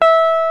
FLYING V 3.wav